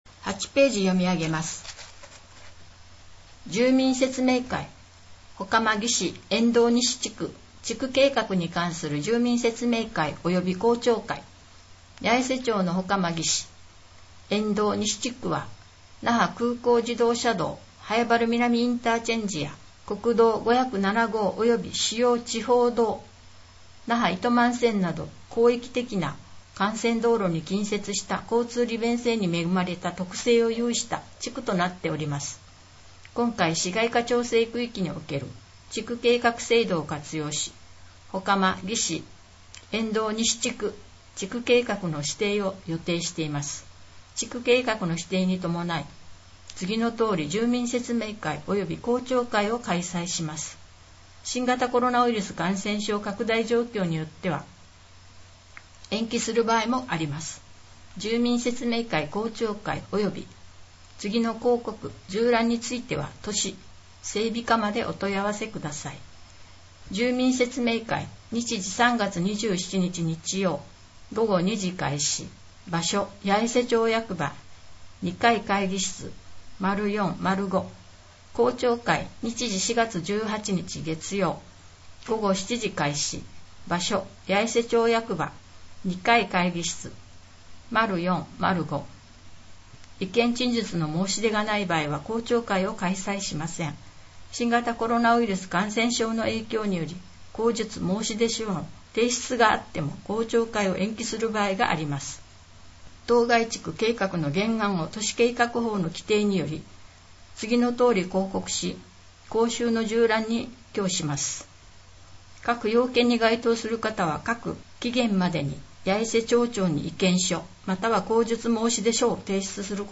この音声は「音訳サークルやえせ」の皆さんのご協力で作成しています。